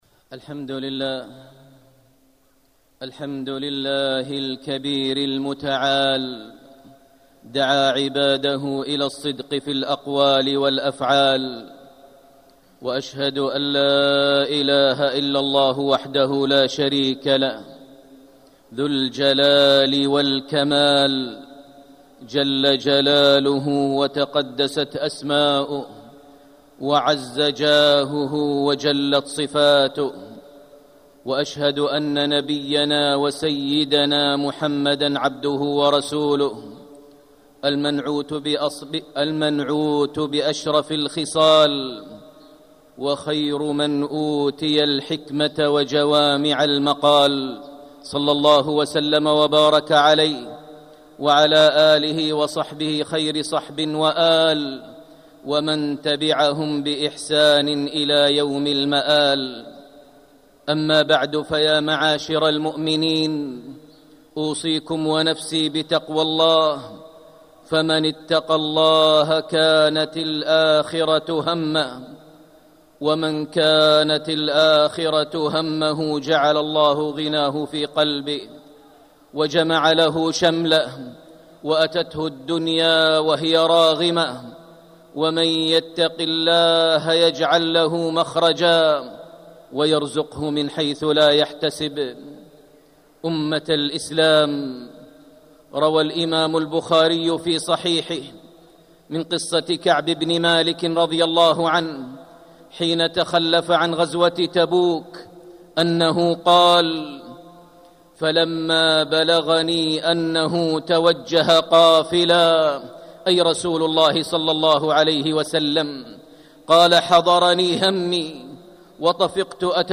مكة: قصة الصحابي كعب بن مالك رضي الله عنه في غزوة تبوك - ماهر بن حمد المعيقلي (صوت - جودة عالية